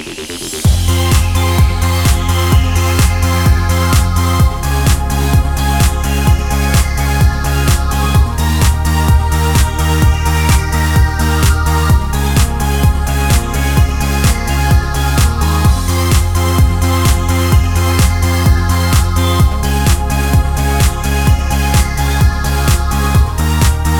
no Backing Vocals R'n'B / Hip Hop 4:15 Buy £1.50